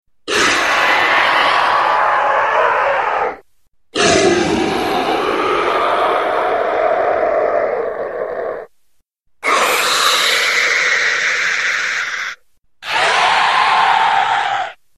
Sea Serpent scream
Tags: Noises Random